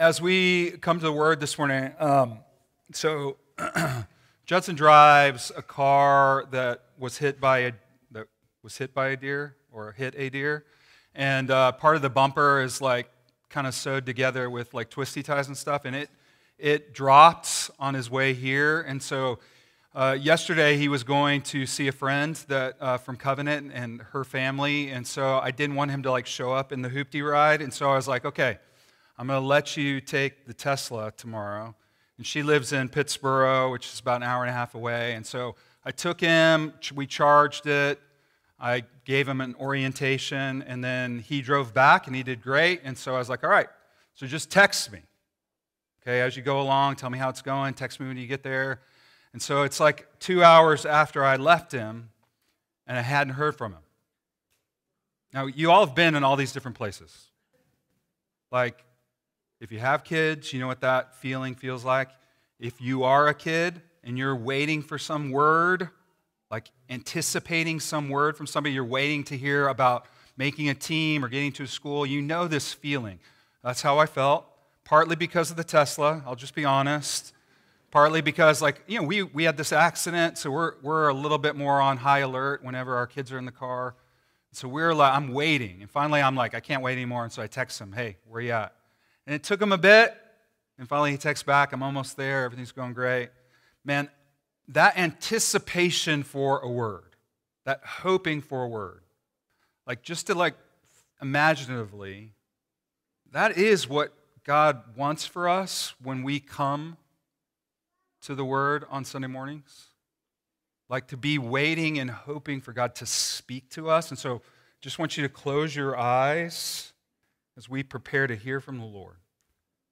11.30 sermon - Made with Clipchamp.m4a